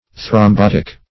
thrombotic.mp3